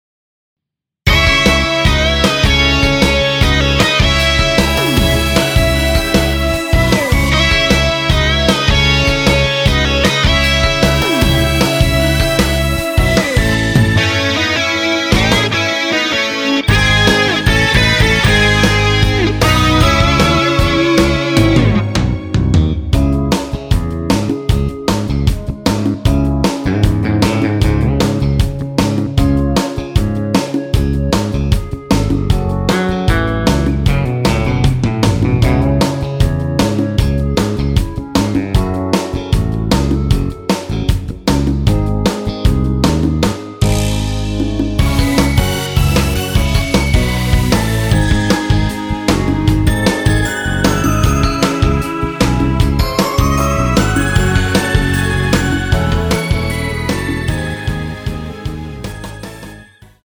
원키 멜로디 포함된 MR 입니다.(미리듣기 참조)
C#m
앞부분30초, 뒷부분30초씩 편집해서 올려 드리고 있습니다.
중간에 음이 끈어지고 다시 나오는 이유는